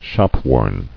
[shop·worn]